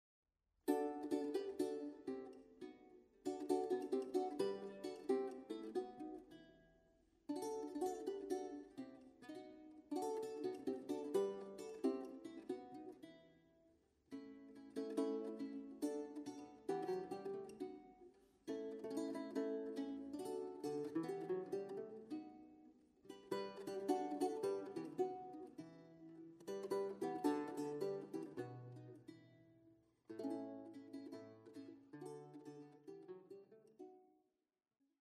liuto, chitarra